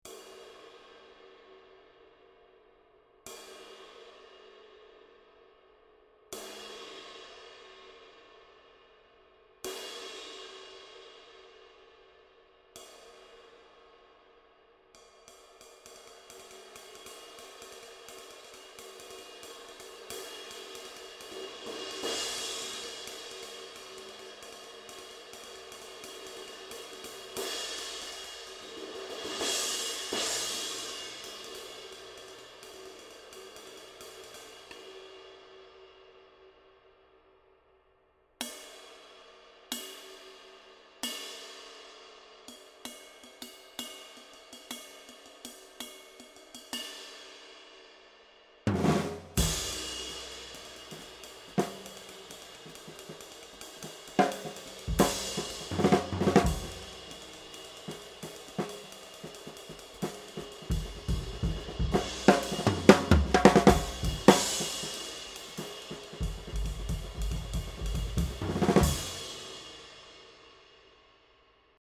20" 1600 Era Ride 1598g